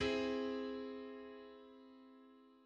Augmented triad on C
An augmented triad is a major triad whose fifth has been raised by a chromatic semitone; it is the principal harmony of the whole tone scale.
Augmented_triad_on_C.mid.mp3